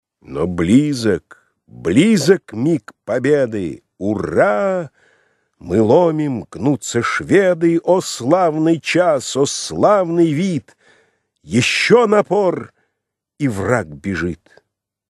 файл) 236 Кб Поэма «Полтава» А.С.Пушкина. Песнь третья (отрывок). Художественное чтение 1